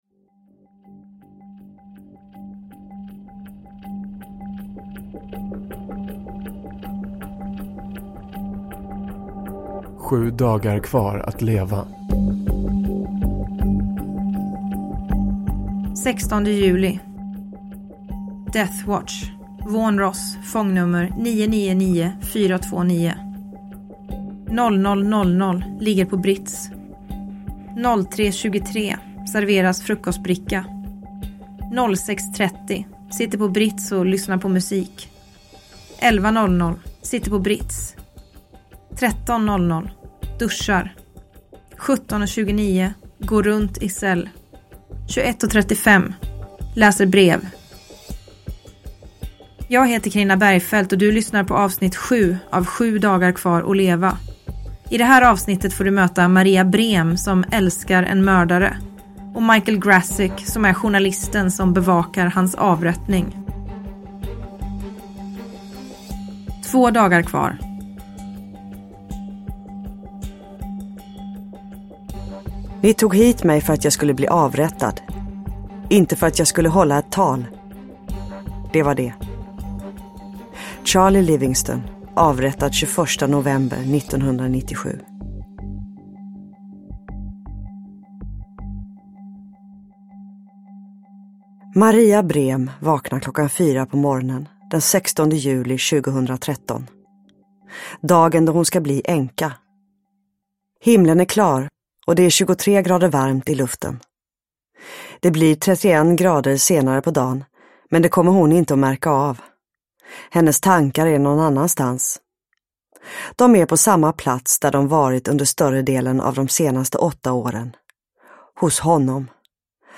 S1A7, Sju dagar kvar att leva – Ljudbok – Laddas ner